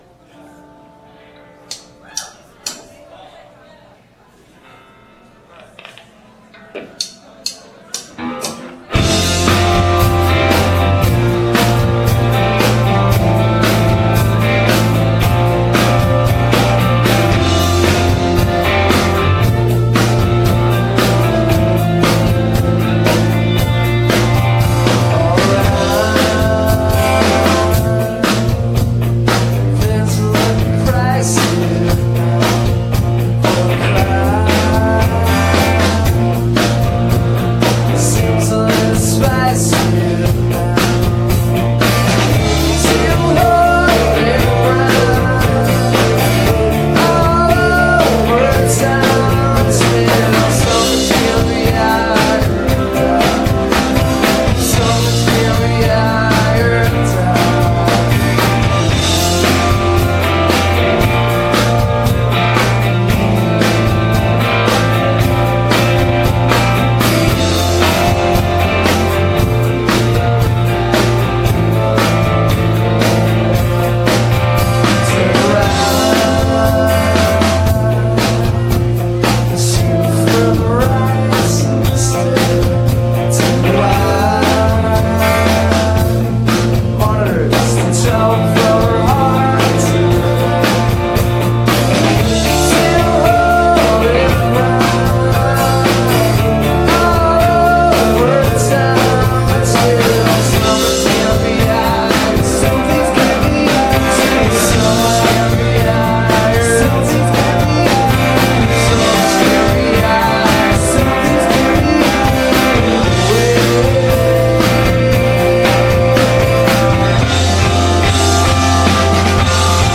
the casbah san diego september 17 2000